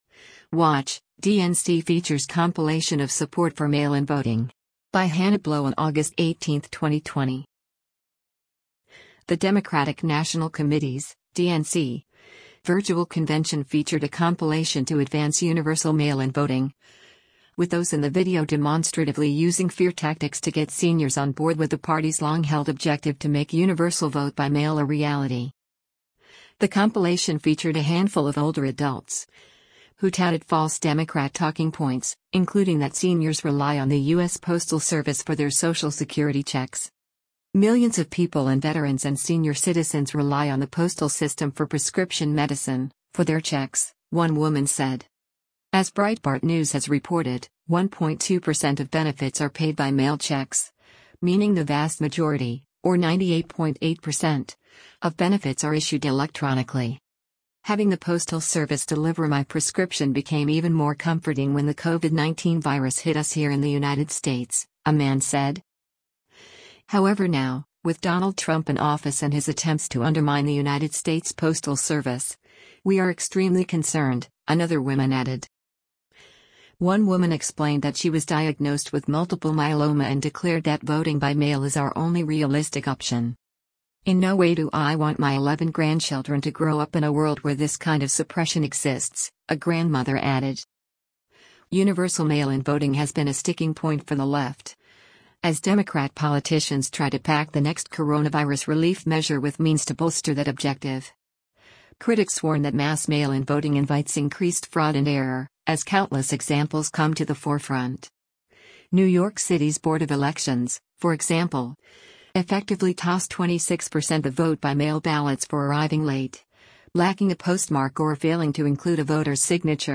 The compilation featured a handful of older adults, who touted false Democrat talking points, including that seniors rely on the U.S. Postal Service for their social security checks.